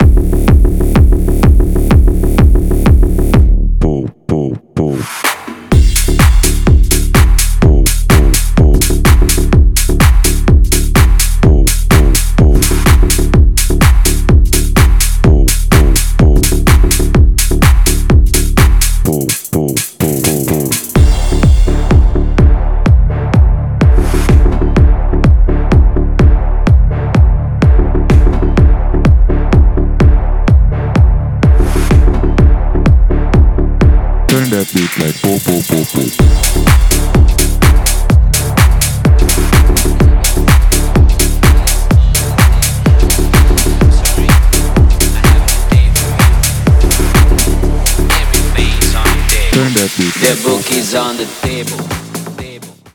• Качество: 320, Stereo
мощные
мощные басы
Bass House
клубная музыка